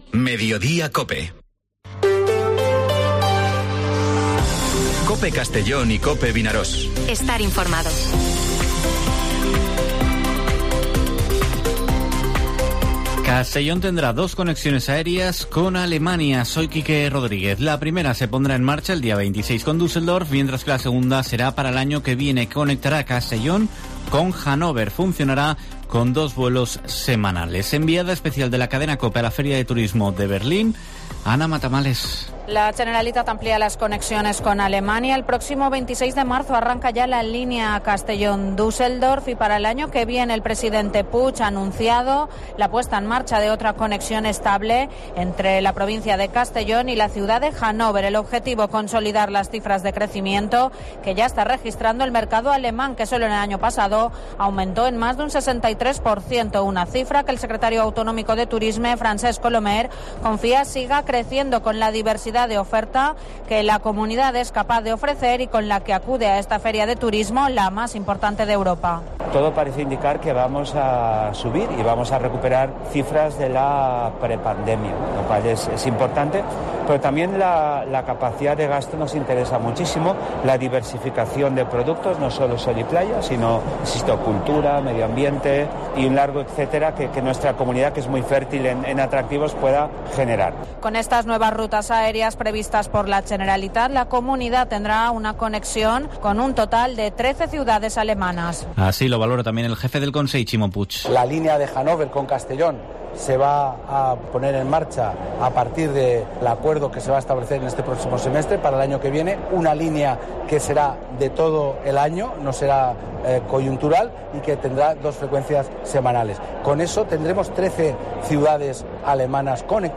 Informativo Herrera en COPE en la provincia de Castellón (07/03/2023)